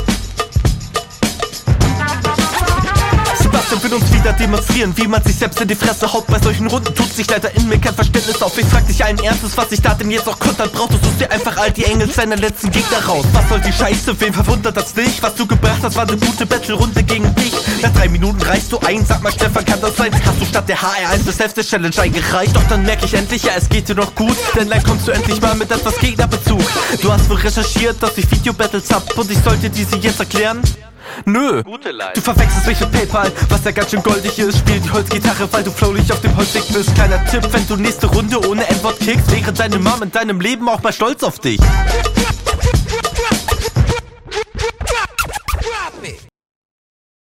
Flow ist ab manchen Stellen noch bisschen wackelig, sonst auch voll gut!